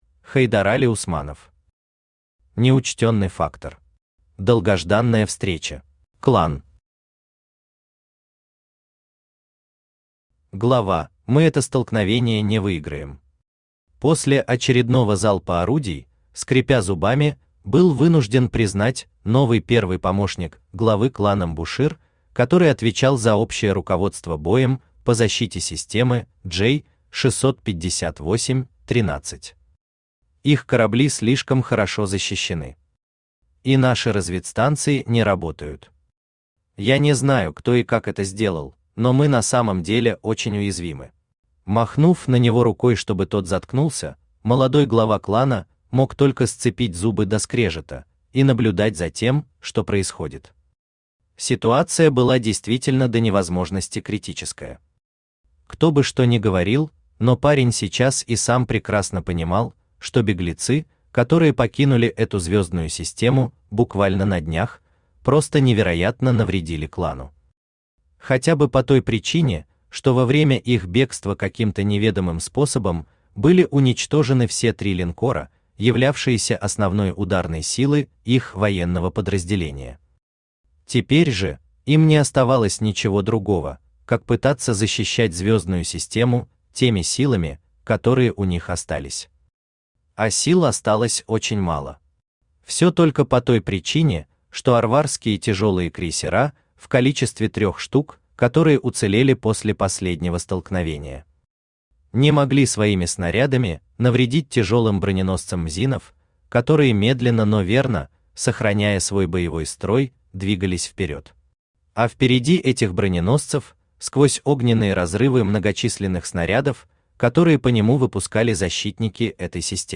Аудиокнига Неучтённый фактор. Долгожданная встреча | Библиотека аудиокниг
Долгожданная встреча Автор Хайдарали Усманов Читает аудиокнигу Авточтец ЛитРес.